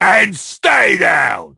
bull_kill_vo_01.ogg